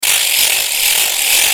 Ratchet
Ratchet.mp3